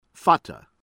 FAROOQI, AMJAD HUSSAIN ahm-JAHD   hoo-SAYN    fah-roo-KEE